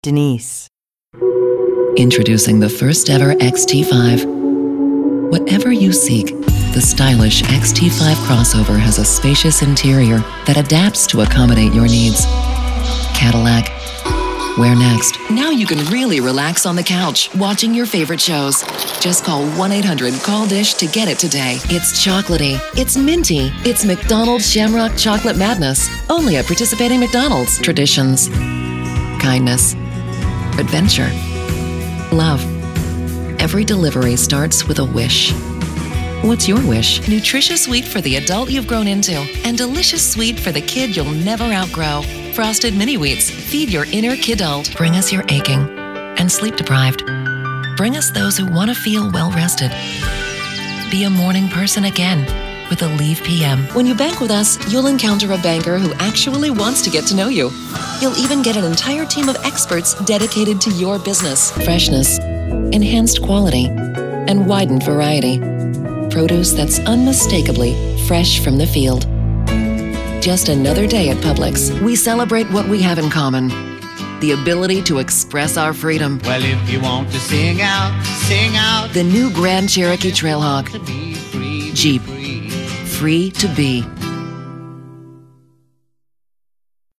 Commercial Reel
anti-announcer, authoritative, classy, compelling, confident, cool, corporate, english-showcase, friendly, genuine, Gravitas, mature, mellow, midlife, mother, neutral, nostalgic, professional, promo, retail, sincere, smooth, soft-spoken, upbeat, warm